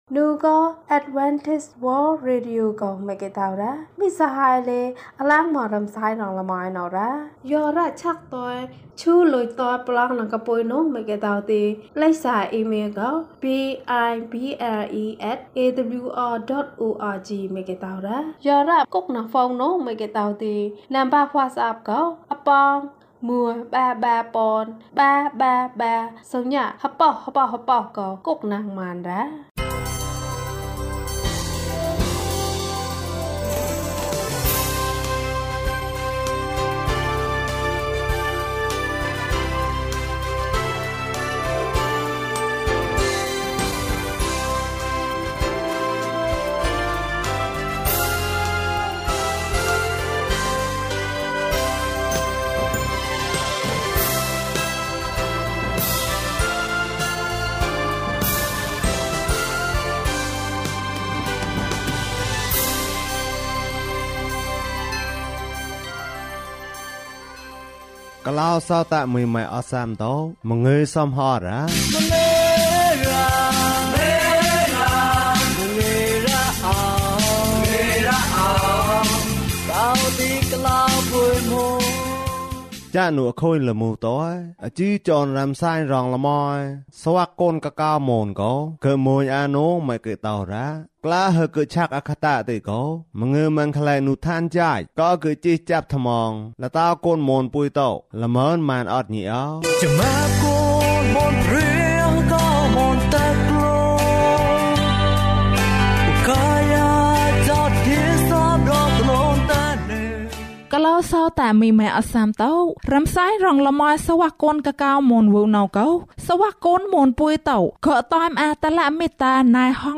သခင်ယေရှု၌ သင်၏ဆုတောင်းချက်ကို ဇွဲမလျှော့နှင့်။ ကျန်းမာခြင်းအကြောင်းအရာ။ ဓမ္မသီချင်း။ တရားဒေသနာ။